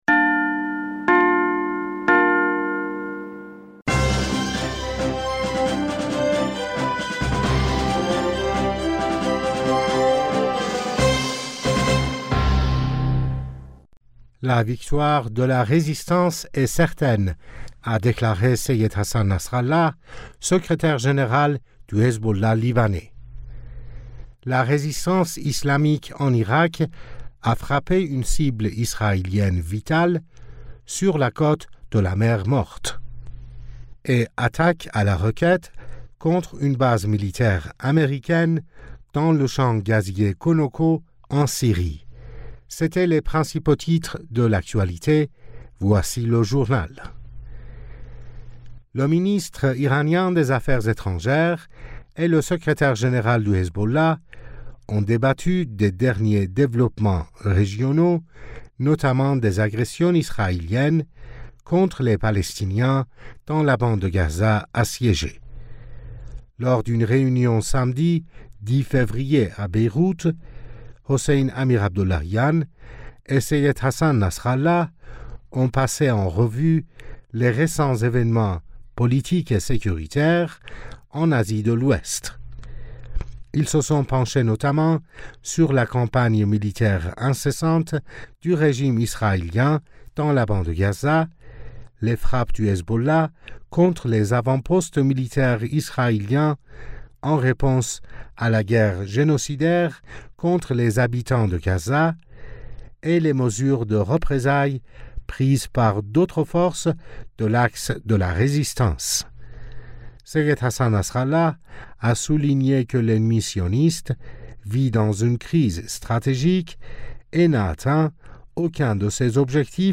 Bulletin d'information du 10 Fevrier 2024